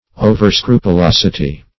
Overscrupulosity \O`ver*scru`pu*los"i*ty\, n.